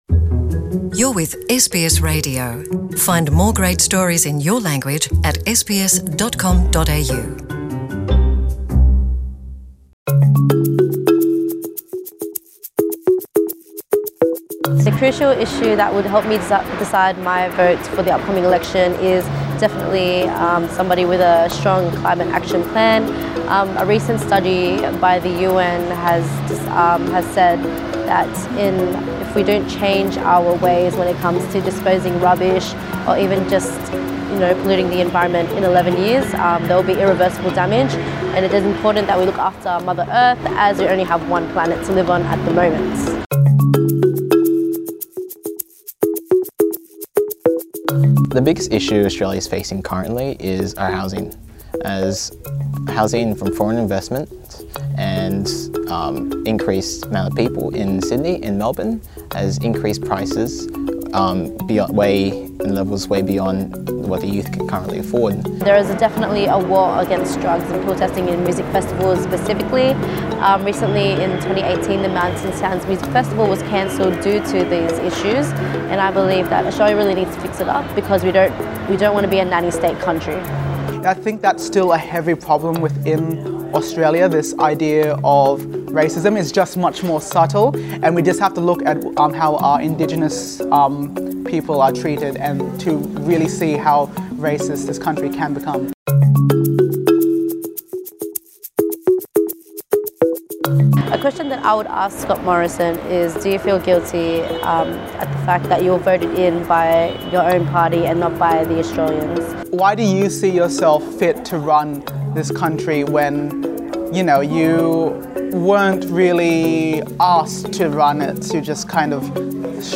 Young Filipino-Australians speak out about their concerns and opinions about the current Australian government system and the upcoming election.